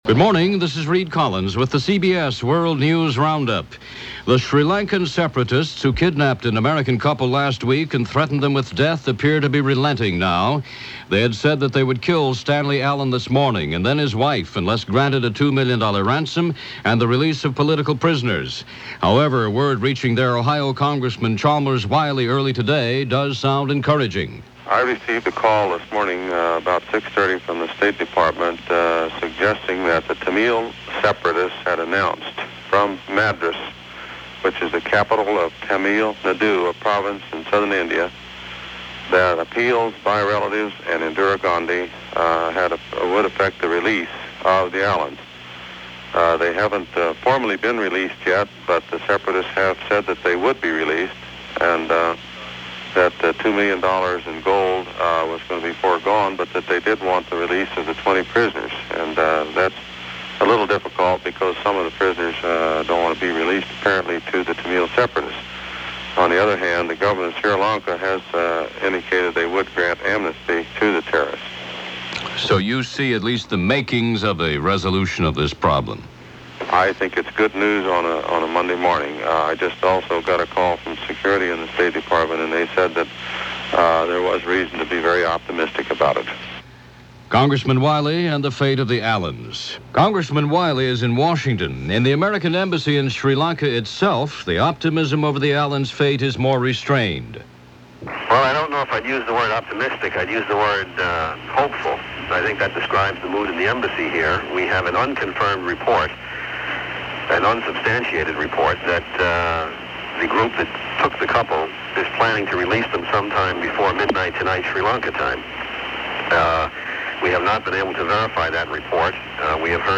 And that’s a small slice of what went on this May 14th in 1984, as presented by Reid Collins and The CBS World News Roundup.